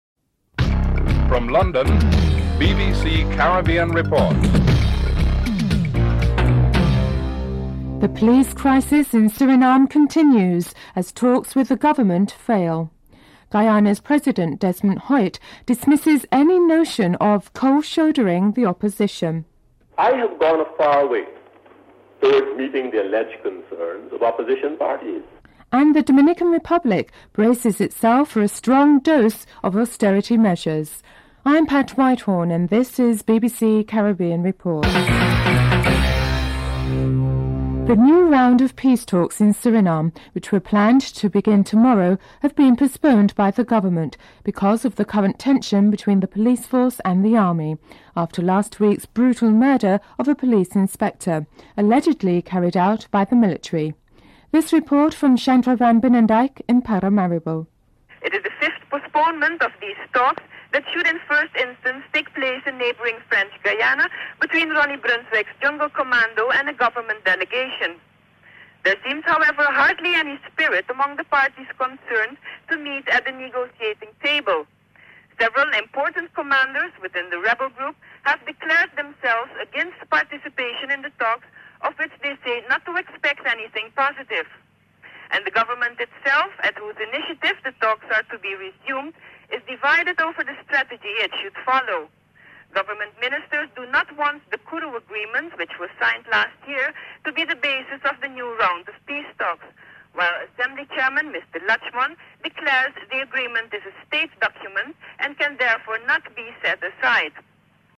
1. Headlines (00:00-00:37)
3. Guyana's president, Desmond Hoyte, marks five years in office this week and discusses his achievements and the question of electoral reforms during a press conference.
4. Financial News (07:48-08:41)